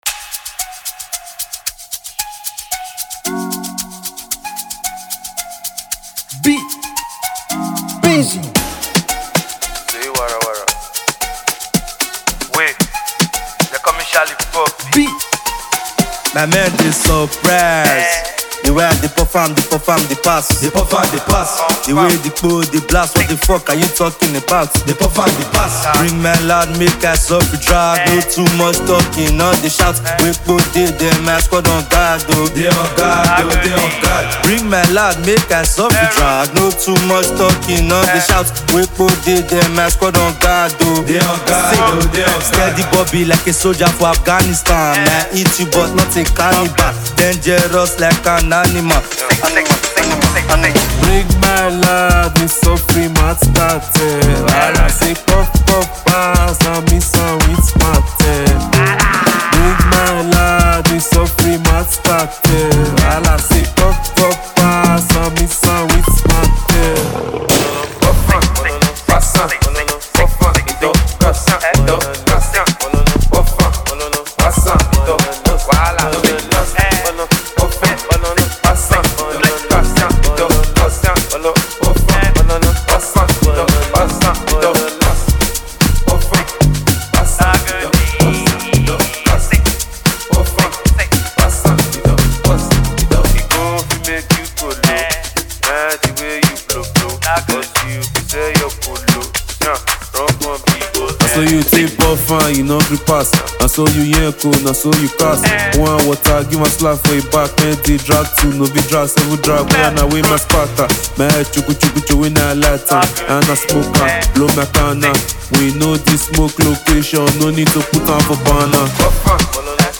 Fast-rising Nigerian afro-pop singer and songwriter